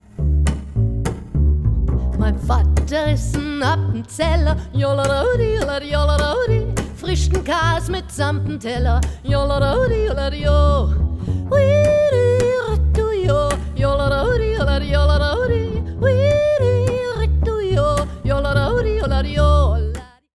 Eins der bekanntesten Jodel-Lieder überhaupt.
das lustige Lied
im Studio aufgenommen